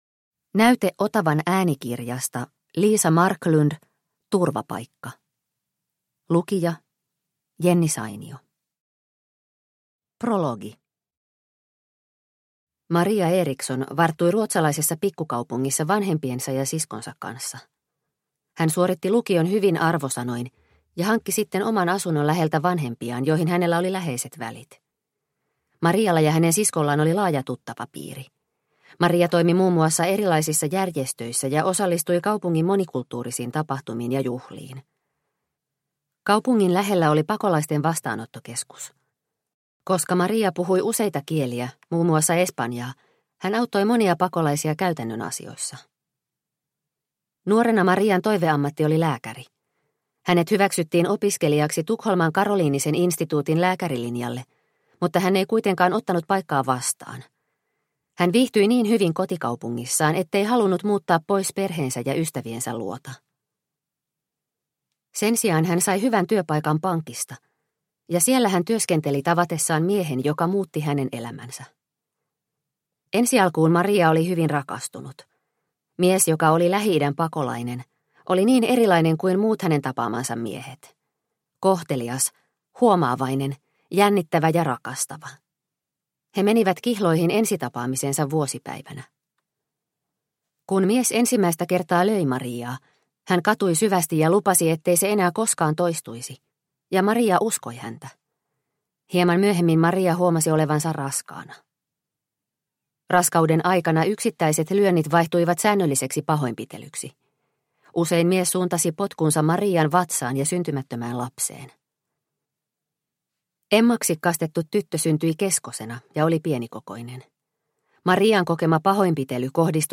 Turvapaikka – Ljudbok – Laddas ner